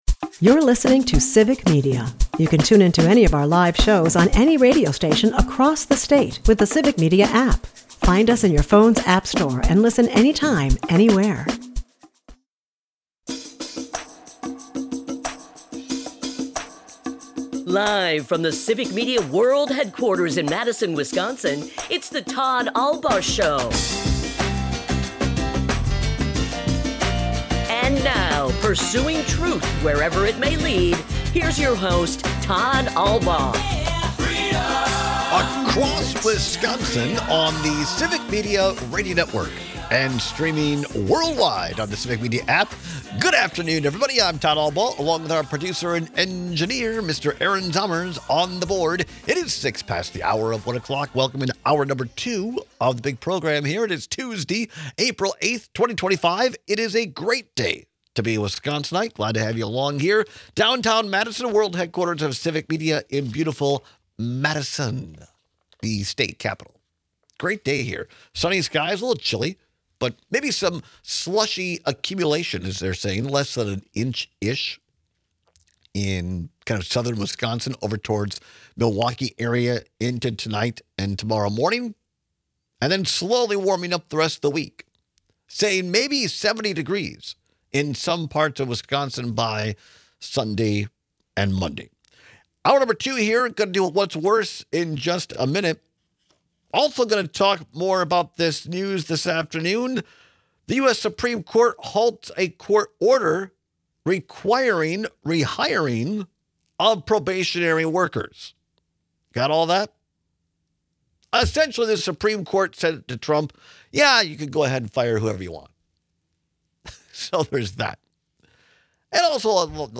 We take calls and texts on climate control.